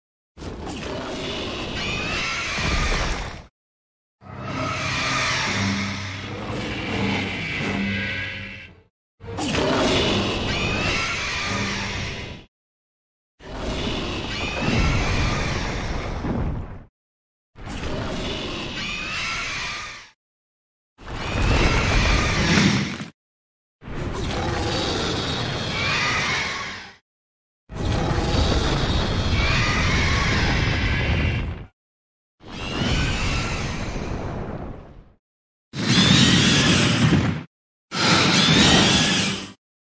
Golba_roars.ogg